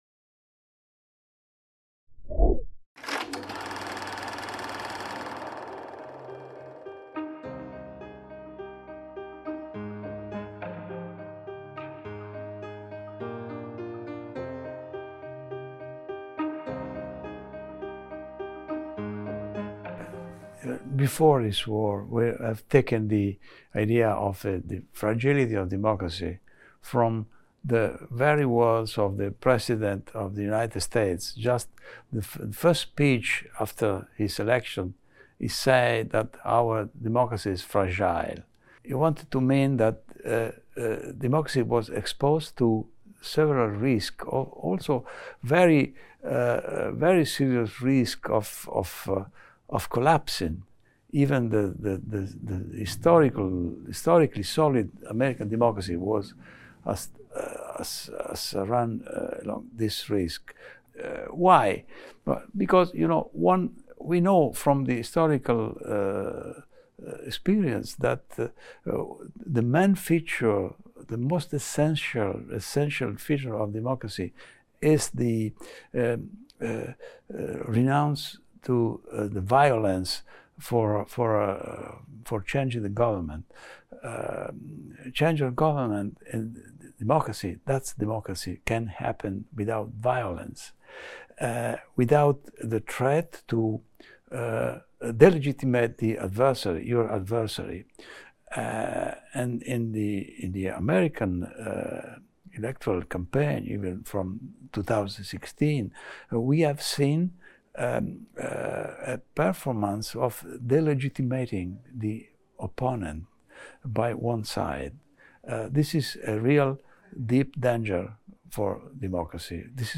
Giancarlo Bosetti's interview about the fragility of democracy